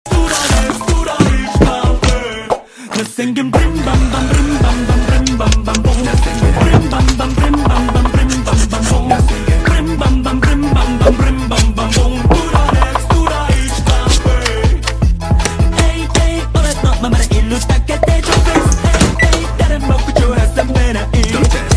Buldak Butter Bread ASMR